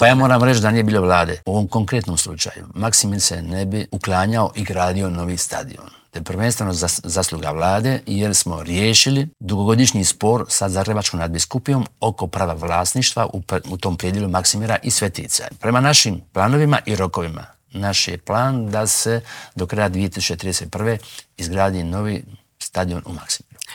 ZAGREB - U nedjelju je obilježena šesta godišnjica zagrebačkog potresa, što se još treba napraviti po pitanju poslijepotresne obnove u Intervjuu tjedna Media servisa pitali smo potpredsjednika Vlade i ministra prostornog uređenja, graditeljstva i državne imovine Branka Bačića.